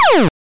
晕针器重装
描述：使用来自freesound的声音重新创建光环needler重新加载声音。
Tag: 科幻 卤素 刺针 重新加载